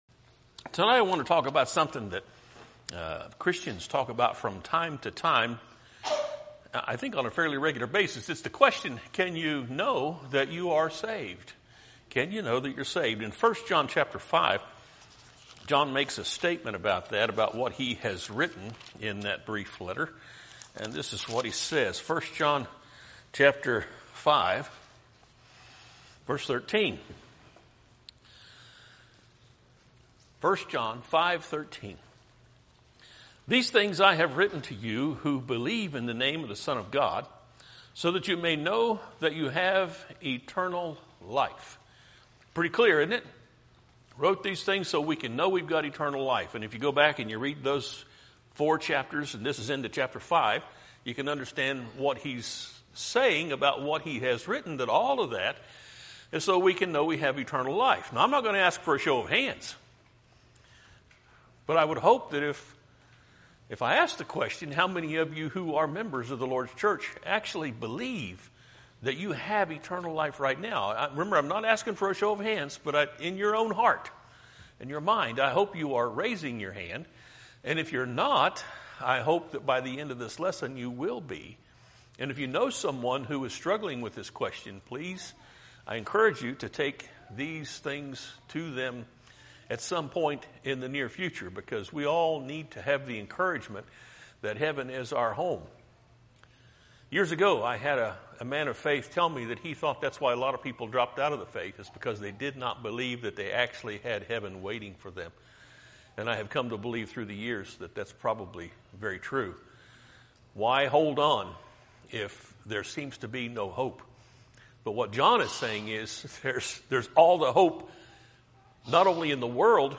Podcasts Videos Series Sermons Can You Know That You Are Saved?